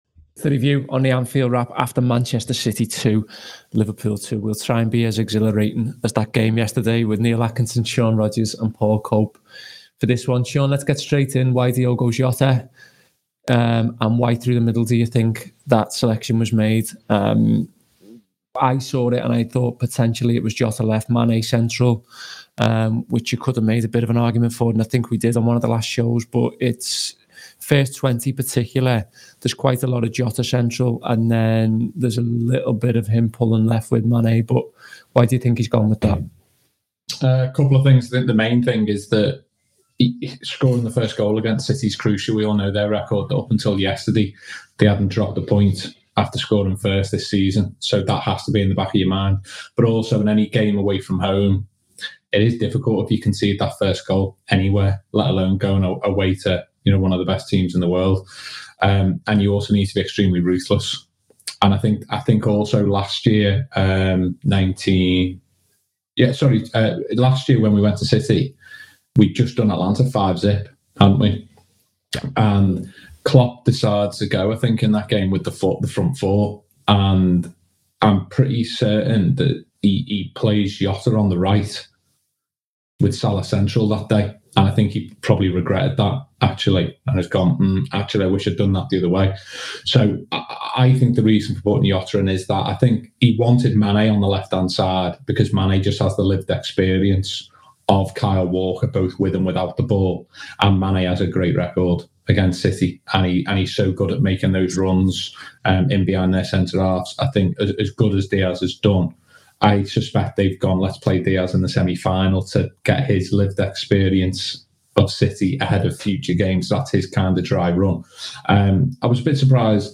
Below is a clip from the show – subscribe for more Manchester City v Liverpool review chat…